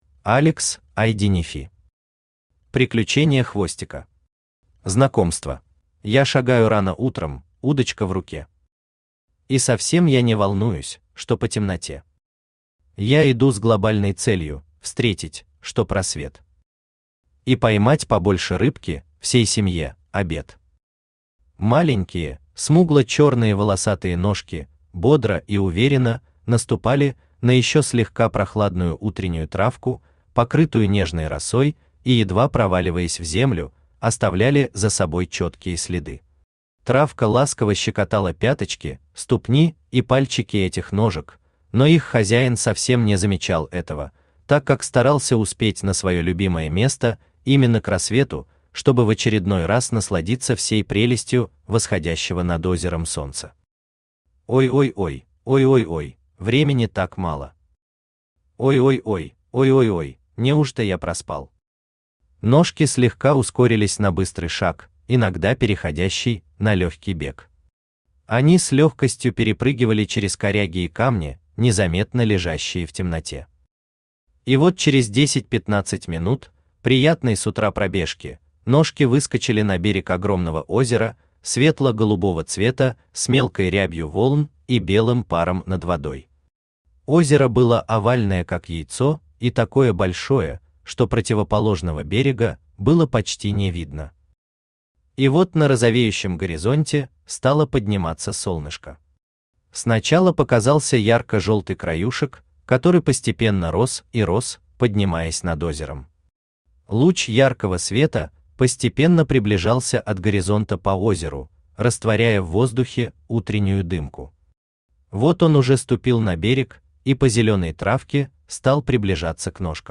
Аудиокнига Приключения Хвостика. Знакомство | Библиотека аудиокниг
Знакомство Автор Алекс Ай Денефи Читает аудиокнигу Авточтец ЛитРес.